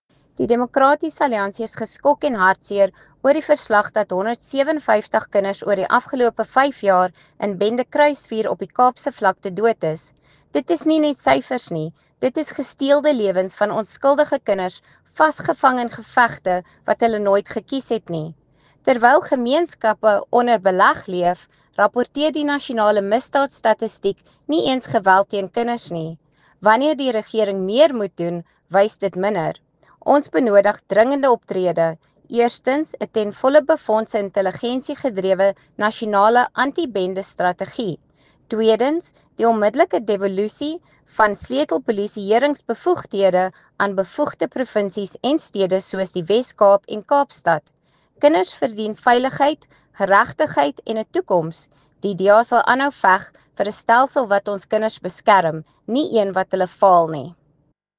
Afrikaans soundbite by Lisa Schickerling MP.